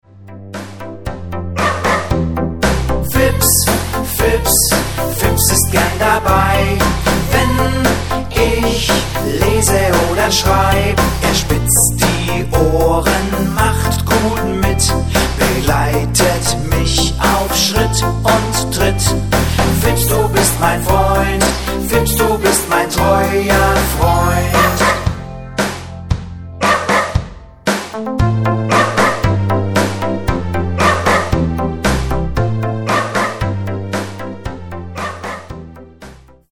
(vocal und playback)